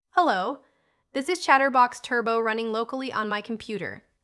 This WAV file is the equivalent of a “hello world” example, generated with default settings and no additional configuration.